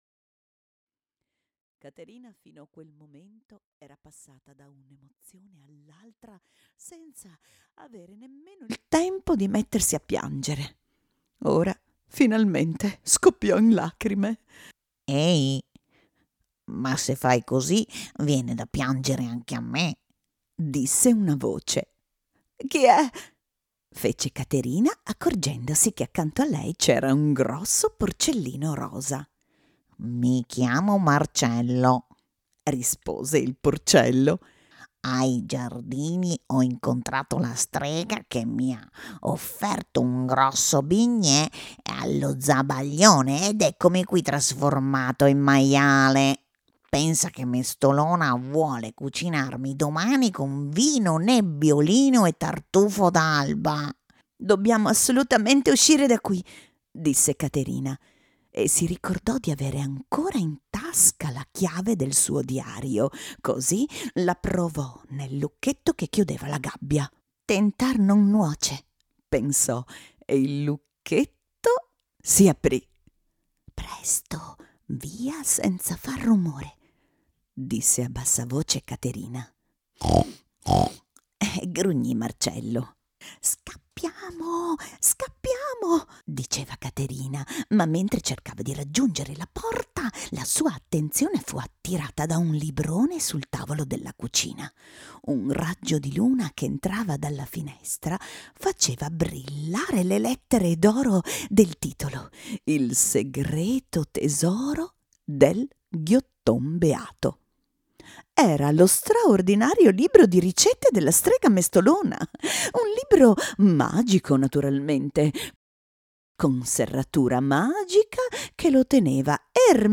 Senza tappeto sonoro
Parte_2_senza_musica _Come_caterina_salvo_babbo_natale.mp3